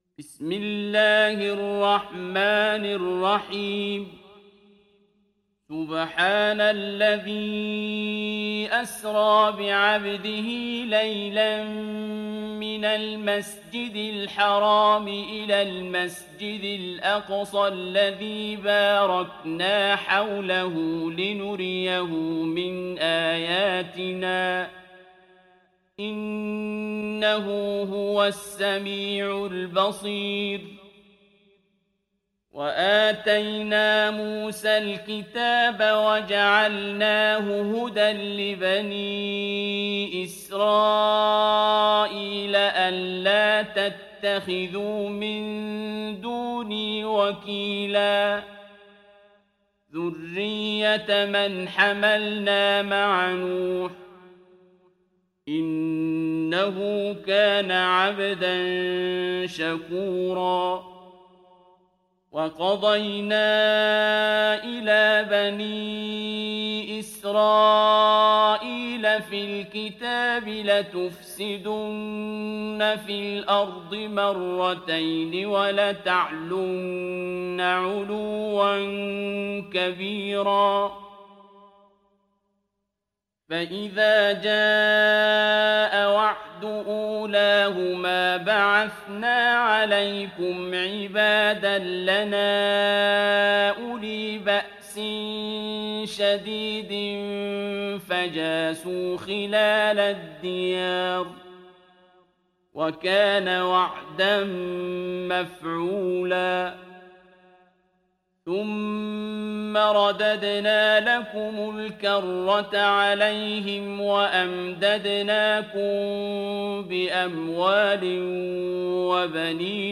Sourate Al Isra Télécharger mp3 Abdul Basit Abd Alsamad Riwayat Hafs an Assim, Téléchargez le Coran et écoutez les liens directs complets mp3
Télécharger Sourate Al Isra Abdul Basit Abd Alsamad